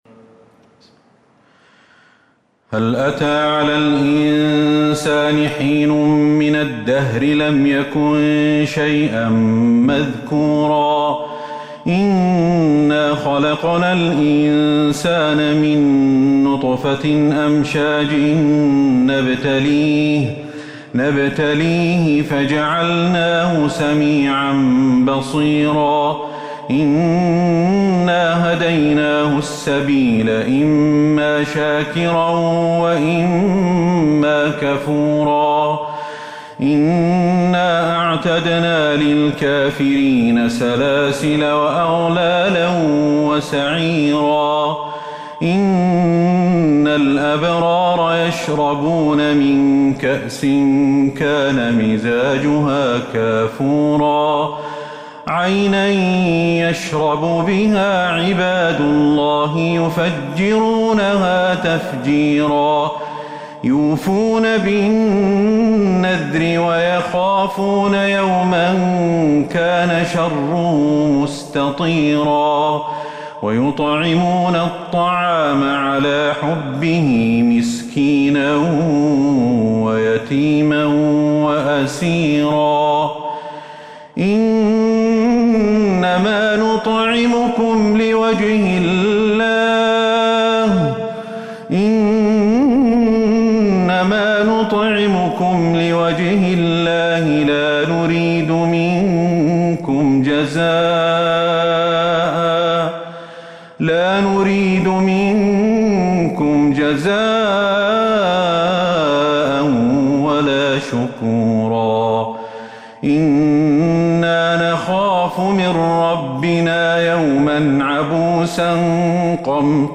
سورة الإنسان Surat Al-Insan من تراويح المسجد النبوي 1442هـ > مصحف تراويح الحرم النبوي عام 1442هـ > المصحف - تلاوات الحرمين